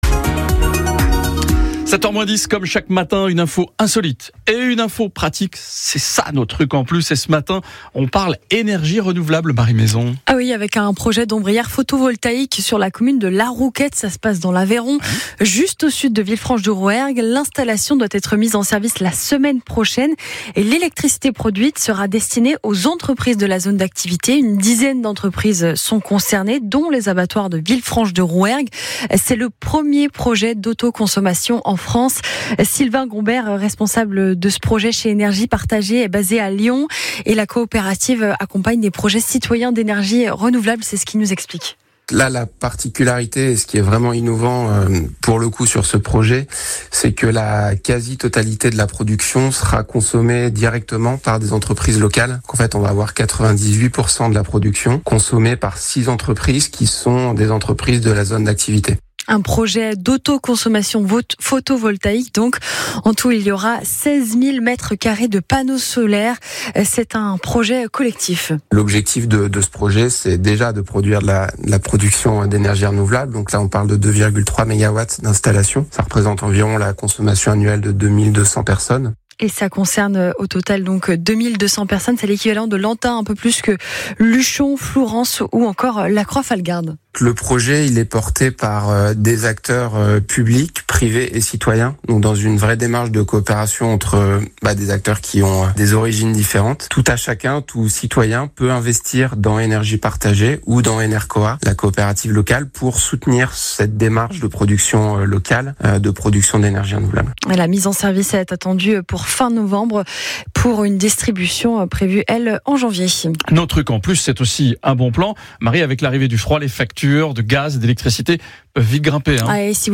La radio Ici Occitanie (anciennement France Bleue Occitanie) a diffusé dans sa matinale un reportage dédié à la mise en service des ombrières photovoltaïques de La Glèbe, dans l'Aveyron.